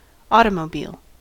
automobile: Wikimedia Commons US English Pronunciations
En-us-automobile.WAV